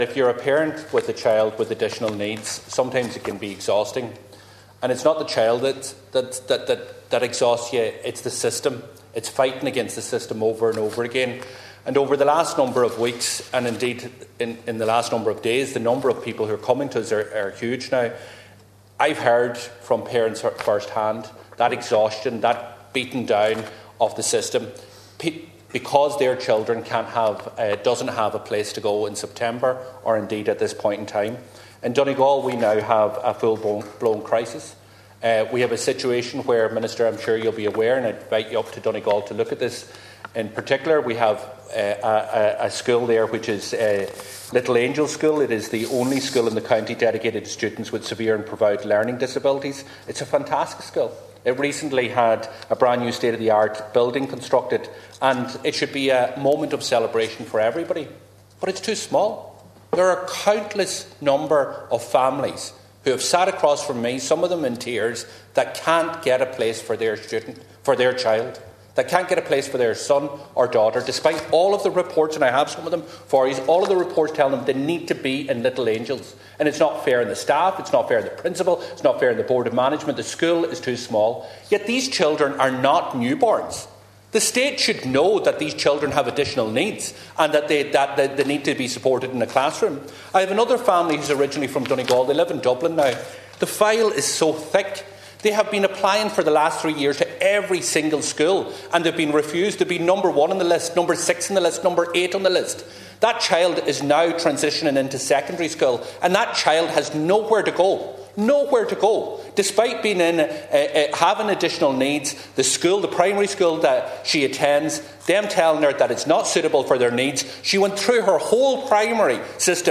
Urging MInister Norma Foley to visit the new Little Angels School, Deputy Pearse Doherty said what should be a cause for celebration has instead become the manifestation of a major crisis in the county…………..